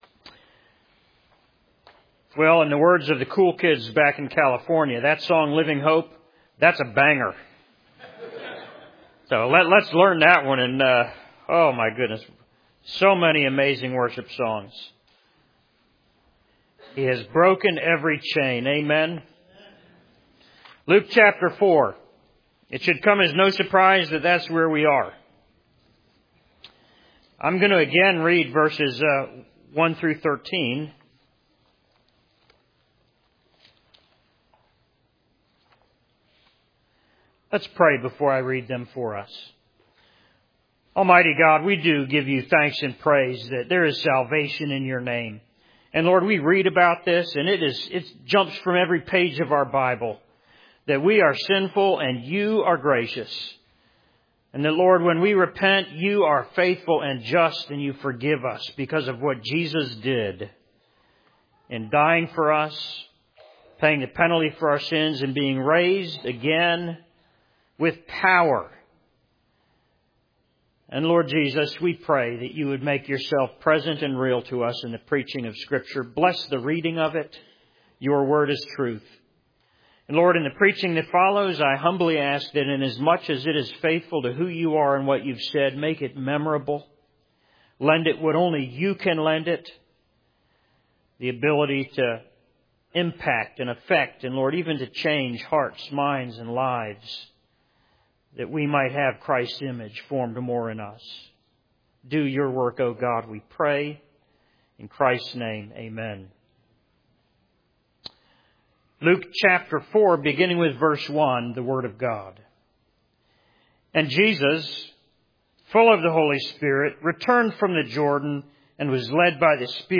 Sermon on Luke 4:1-8: The Second Temptation - Columbia Presbyterian Church